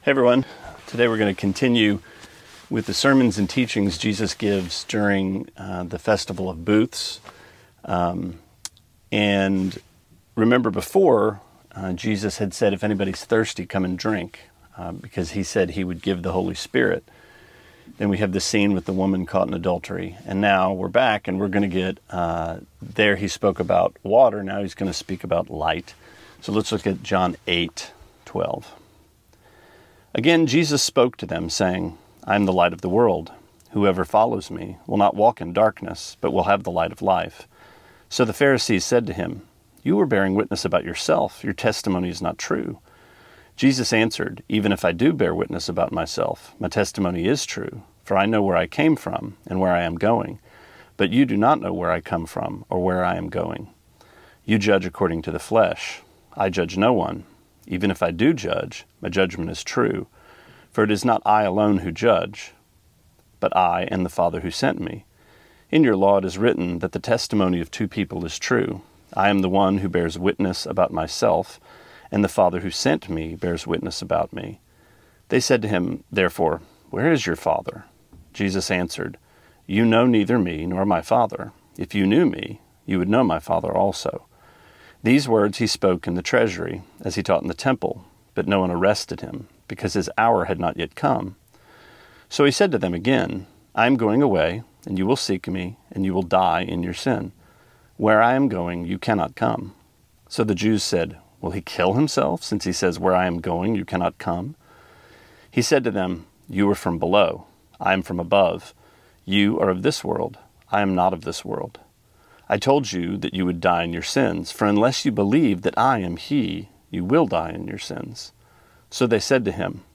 Sermonette 5/14: John 8:12-30: The Light Shines in the Darkness